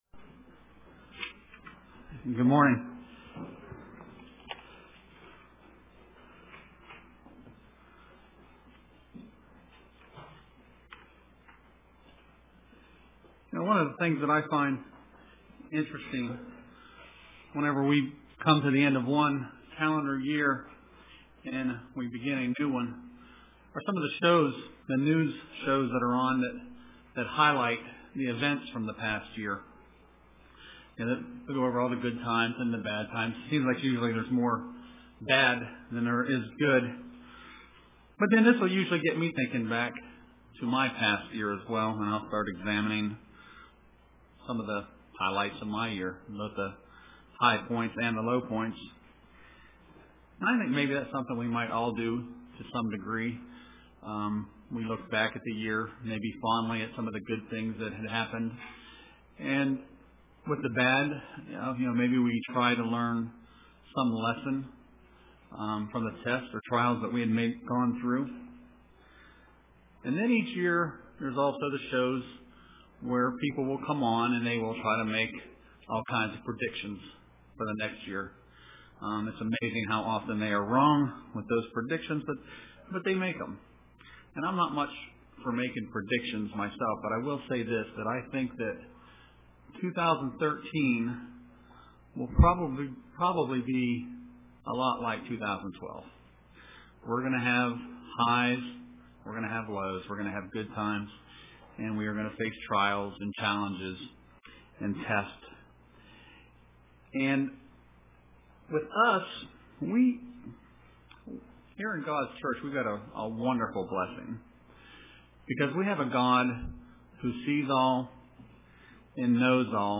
Print My Eyes upon the Lord UCG Sermon Studying the bible?